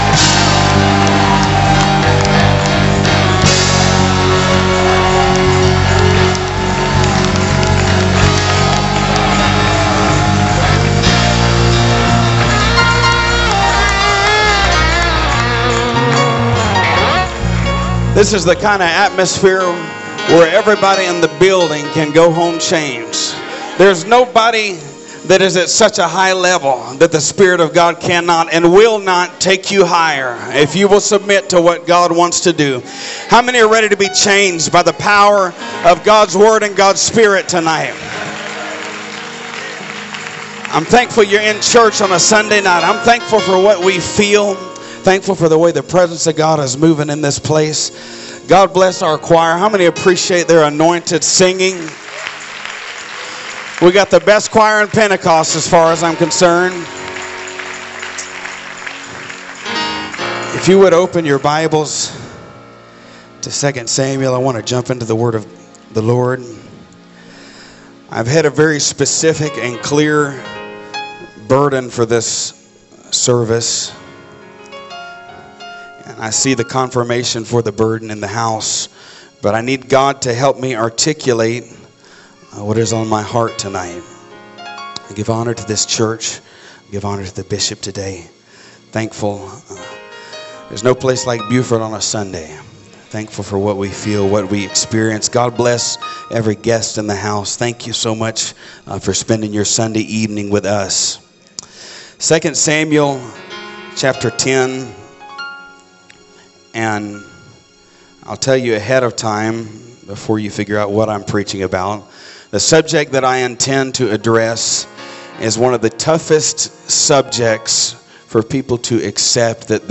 First Pentecostal Church Preaching 2022